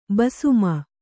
♪ basuma